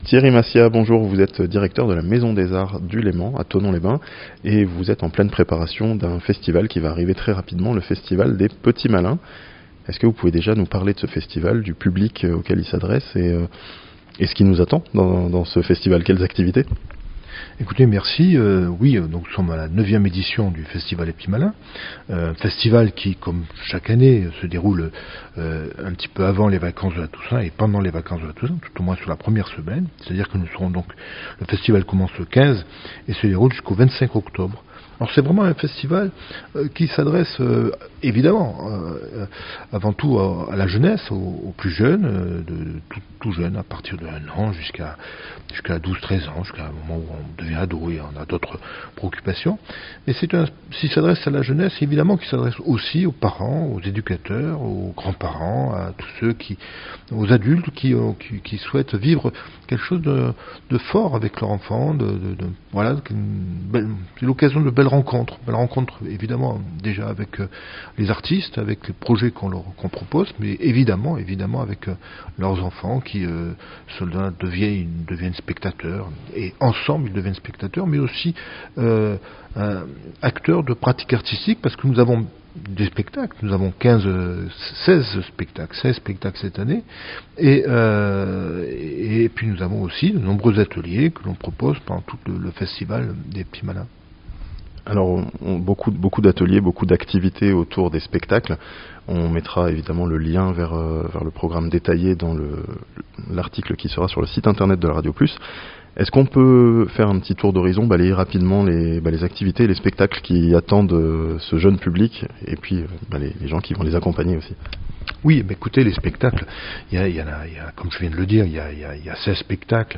A Thonon, 10 jours de festival pour les enfants et les familles (interview)